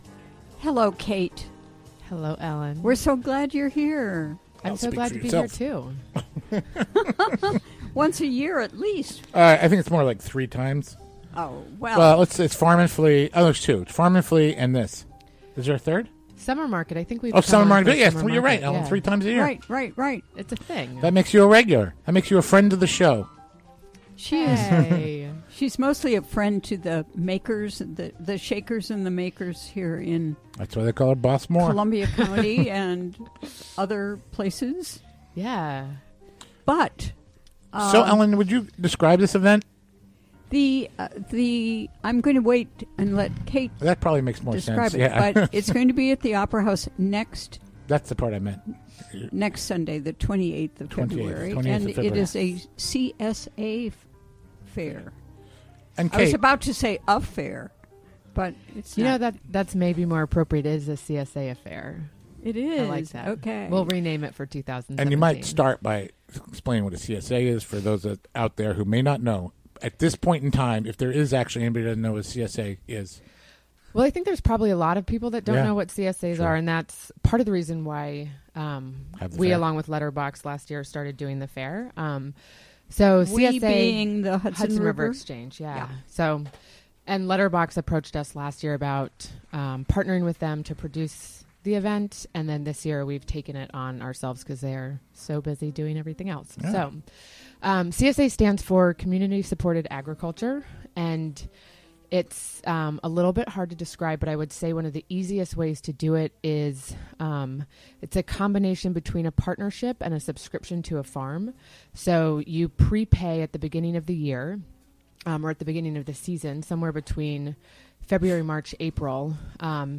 Recorded during the WGXC Afternoon Show, Thu., February 18, 2016.